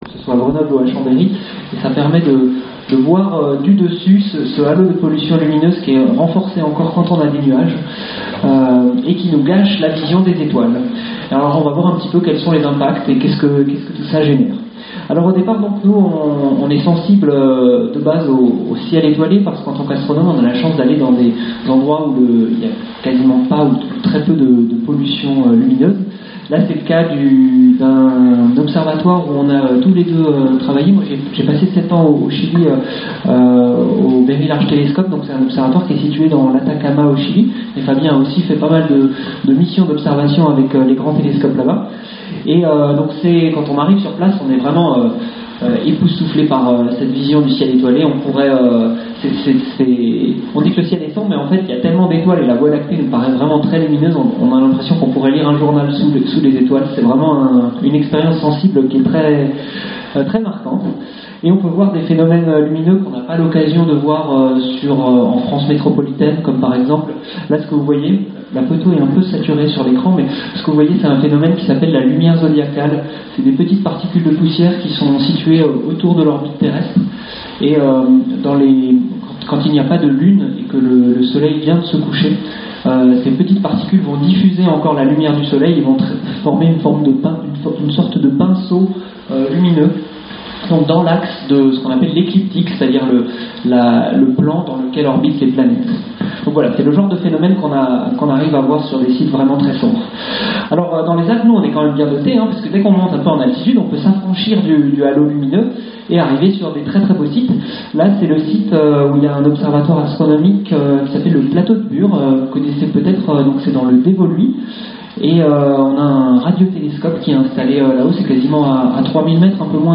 Une fois par mois, à Eurêka, centre de culture scientifique, venez rencontrer des spécialistes, poser vos questions et débattre avec eux lors des rendez-vous « Entre midi & science ».